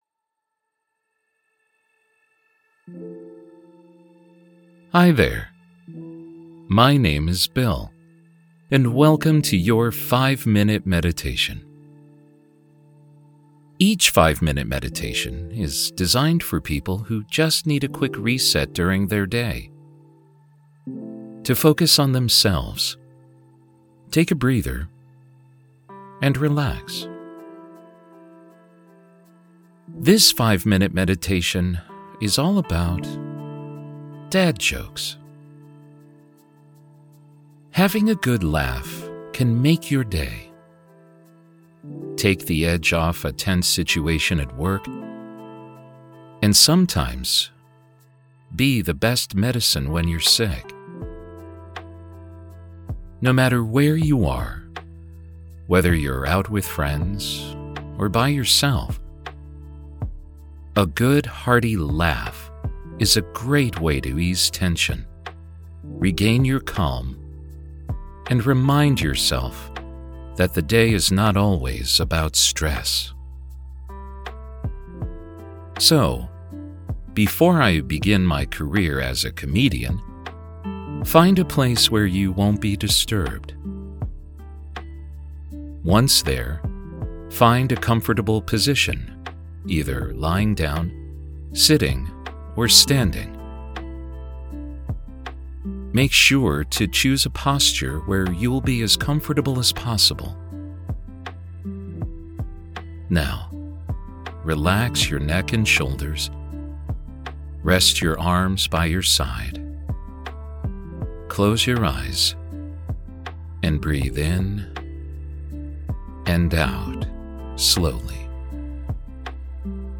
Meditation The sound of rain falling on leaves, or light wind through the trees to help you relax.
5-Minute-Meditation-Dad-Jokes.mp3